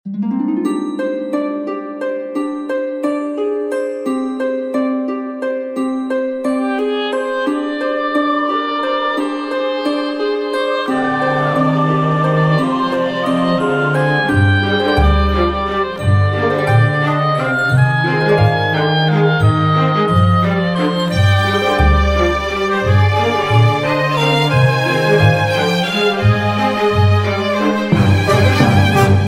رینگتون سنتی ملایم و بی کلام